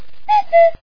GAME_WARN.mp3